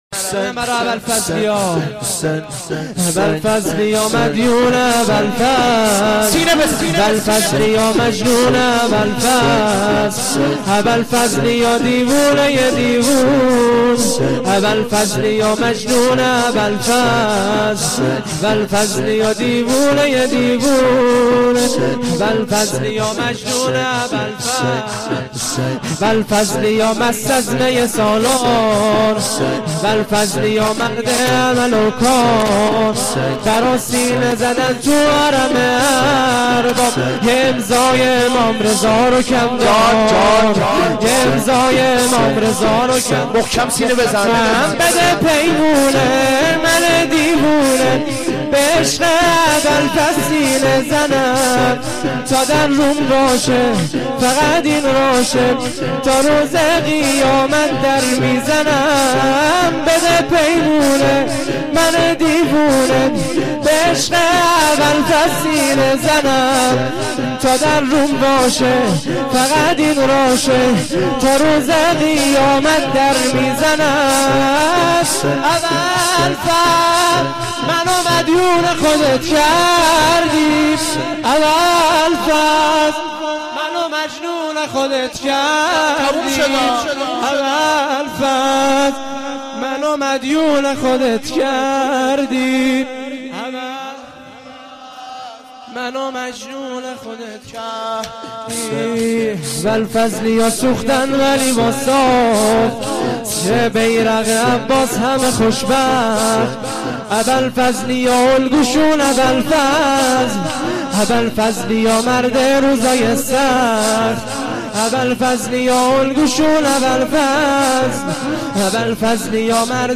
محرم92(شب دوم)